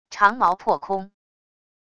长矛破空wav音频